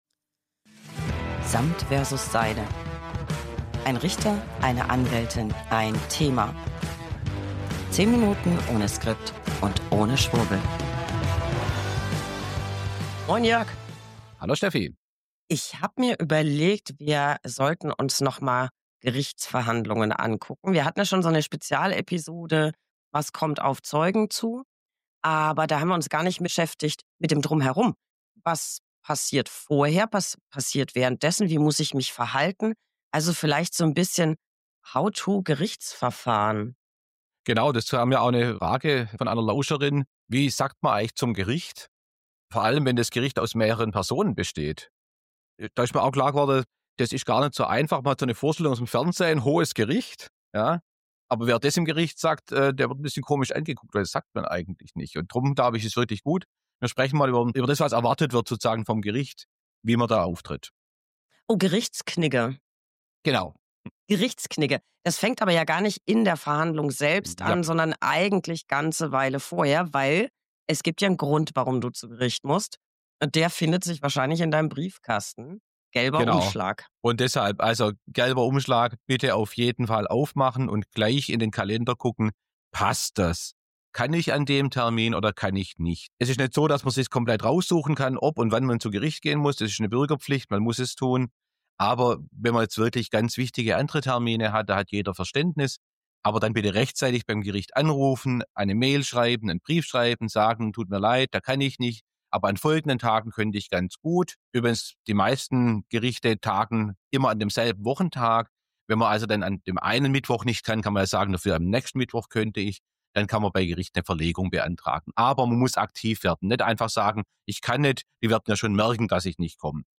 Beschreibung vor 8 Monaten 1 Anwältin + 1 Richter + 1 Thema. 10 Minuten ohne Skript und ohne Schwurbel.